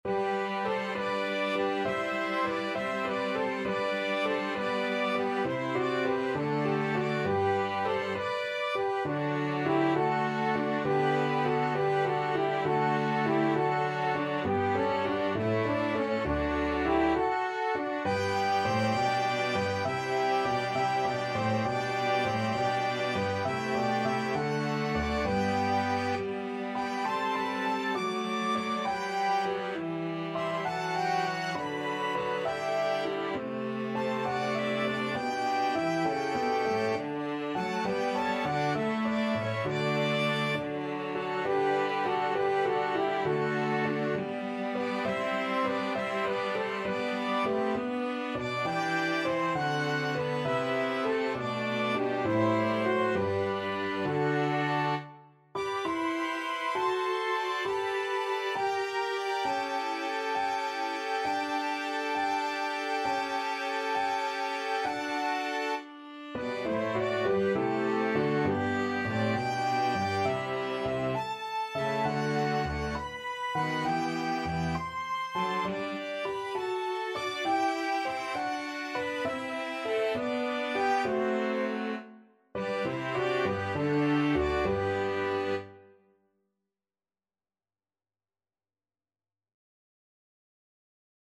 12/8 (View more 12/8 Music)
Largo